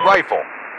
Merged "rifle!" and "missile away!" radio messages
Radio-pilotLaunchRifle1.ogg